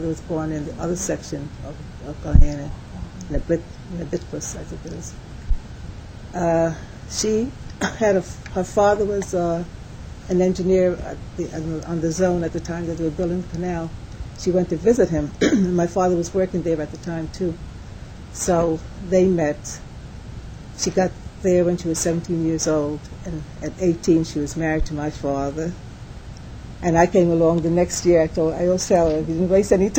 3 audio cassettes